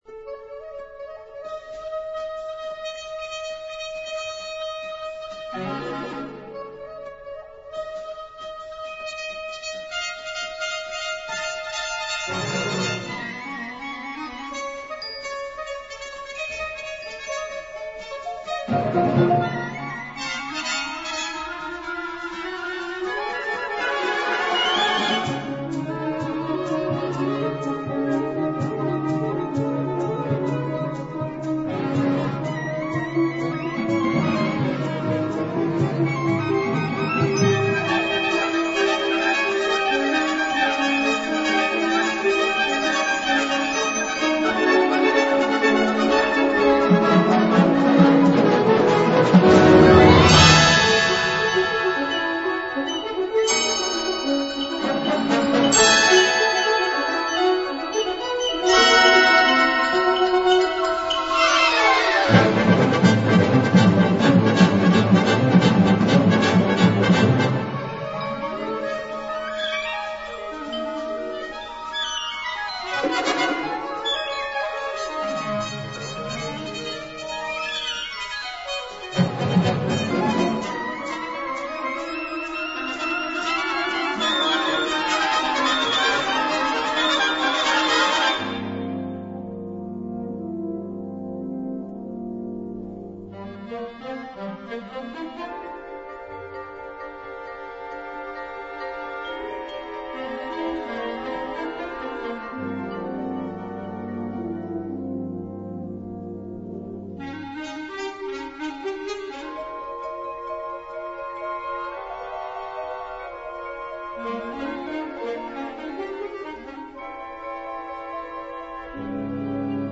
Unterkategorie Zeitgenössische Bläsermusik (1945-heute)
Besetzung Ha (Blasorchester)
Zahlreiche melodische Motive werden ständig umhergewirbelt.
Diese Musik ist bewusst überschwänglich und trendy.